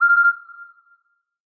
На этой странице собраны звуки из социальной сети Facebook: уведомления, звонки, системные сигналы.
Звуковое оповещение Alert 4